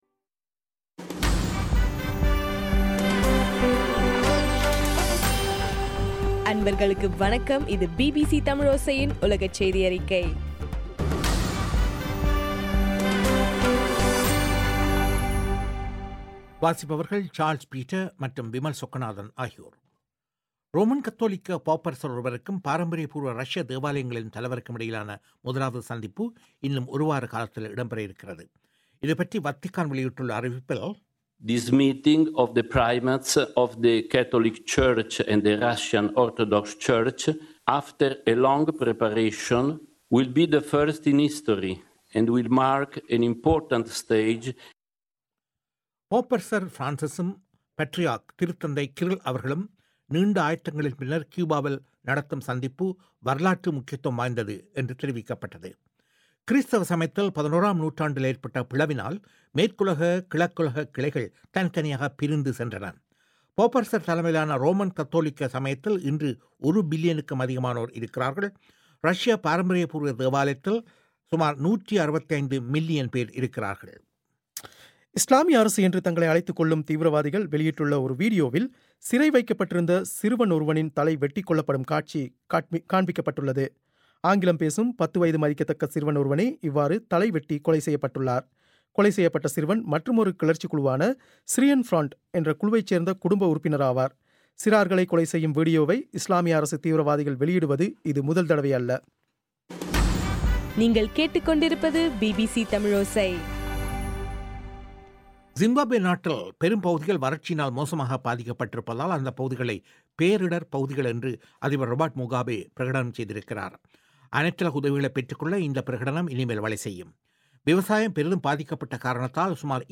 பிப்ரவரி 5, 2016 பிபிசி தமிழோசையின் உலகச் செய்திகள்